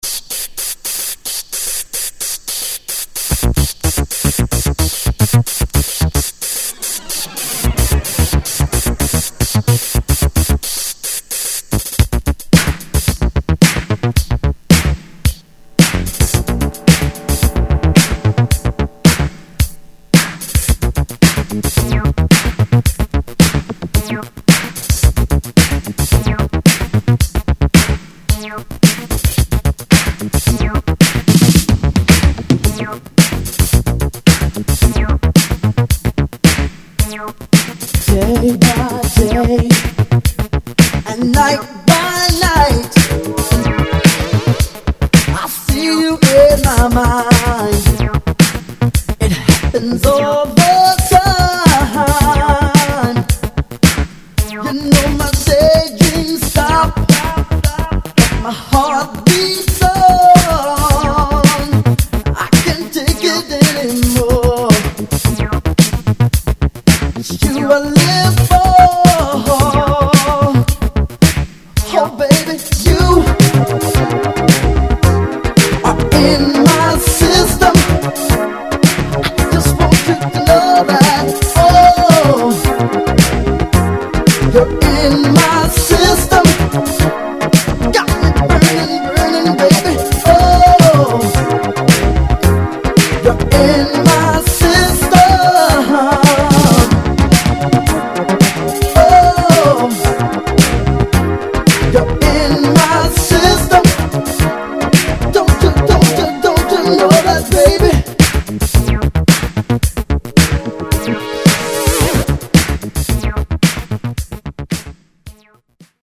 ・ DISCO 70's 12'